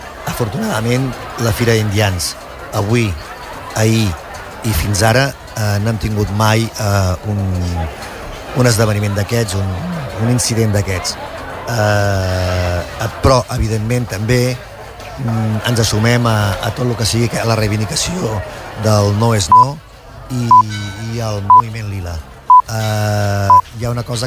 Amb motiu de la quinzena Fira d’Indians de Begur, Ràdio Capital ha pogut entrevistar Eugeni Pibernat, regidor de Promoció Econòmica i Turisme del municipi.
Podeu escoltar l’entrevista completa a Eugeni Pibernat, regidor de Promoció Econòmica i Turisme a Ràdio Capital amb motiu de la XV Fira d’Indians des d’aquesta mateixa entrada.